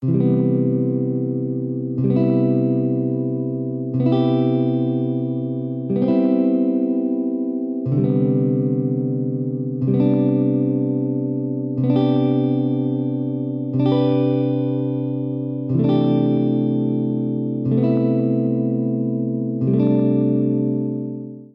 Below are some selected examples of major chord voicings (
Chord Name Cmaj7 Cmaj7 Cmaj7 Cmaj7 Cmaj9 Cmaj9
Chord Name Cmaj9 Cmaj9 Cmaj13 Cmaj69 Cmaj6
major_chords.mp3